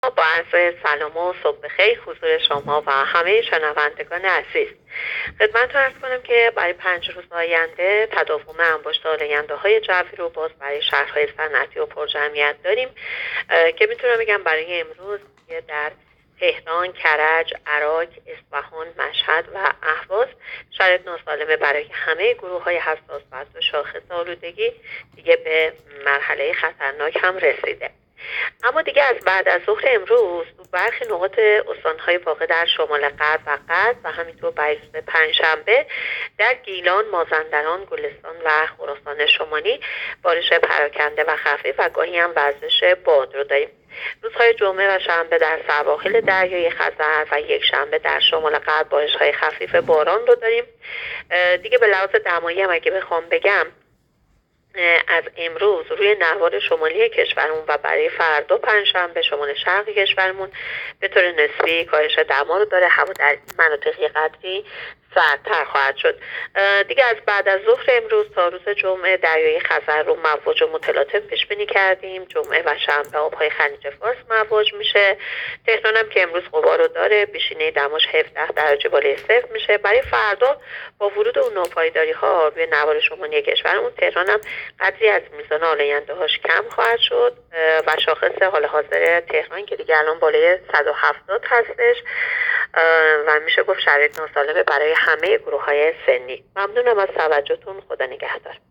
گزارش رادیو اینترنتی پایگاه‌ خبری از آخرین وضعیت آب‌وهوای ۵ آذر؛